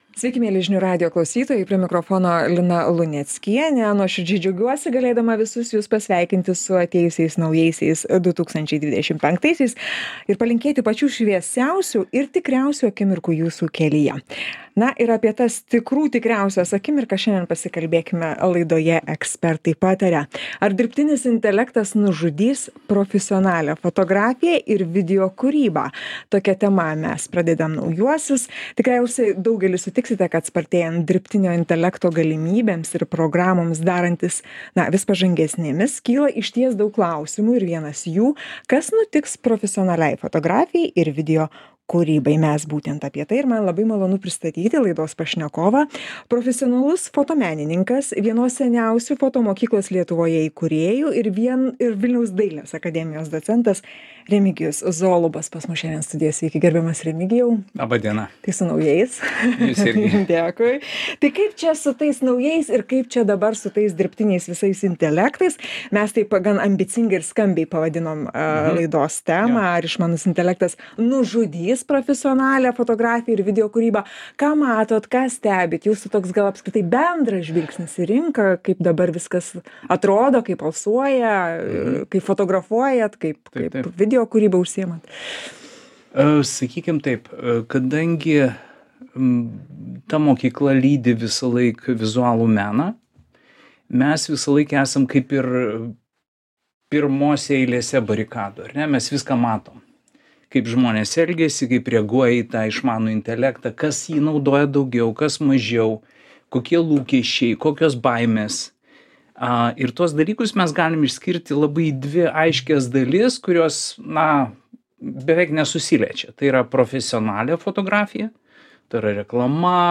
Pokalbis su profesionaliu fotomenininku